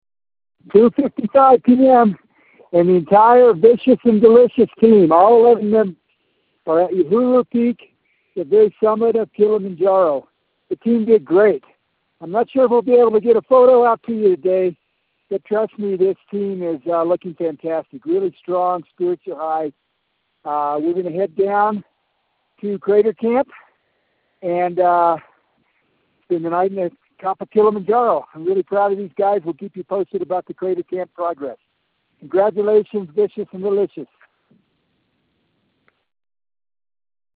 From Uhuru Peak